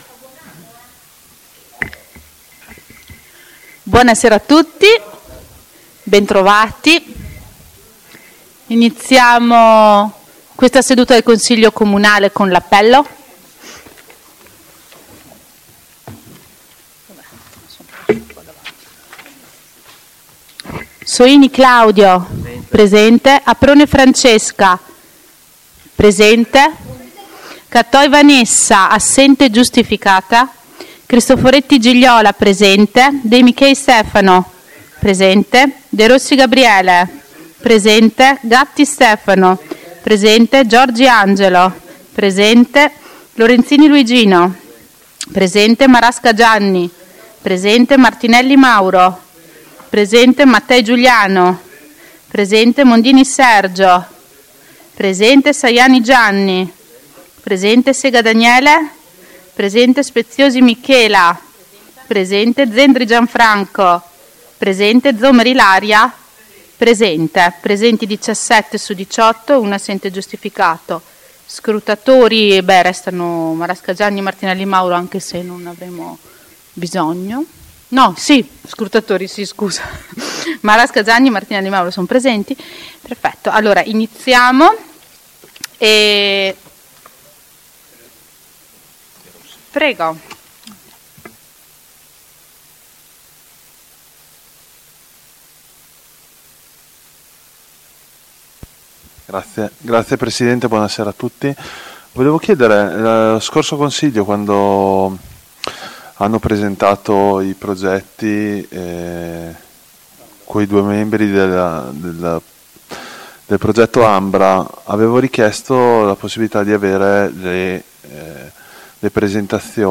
AudioSedutaConsiglio.mp3